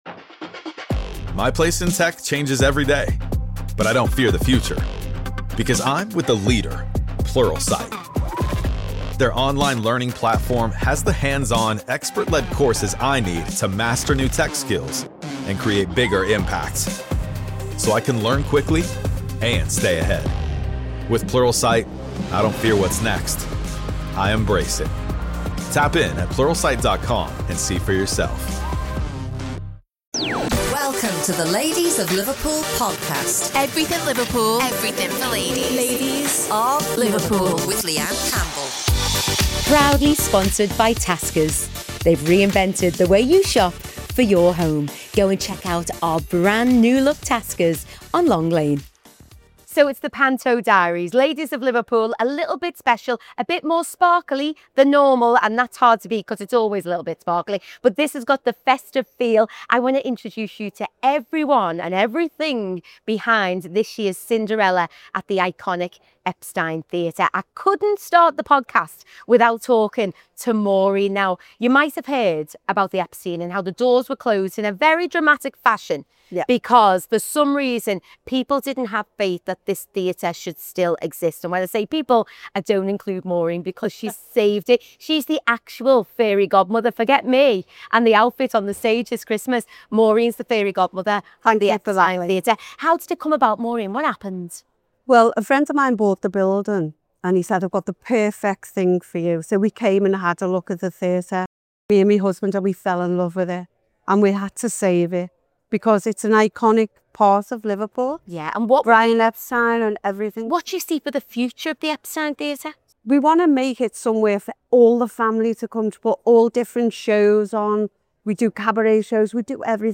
In this week’s episode, we’re stepping behind the curtain at the iconic Epstein Theatre for an exclusive look at the upcoming Cinderella pantomime.
Join us as we chat with the cast and discover how they’re bringing this classic tale to life, and get the inside scoop on what audiences can expect from this year’s festive spectacular.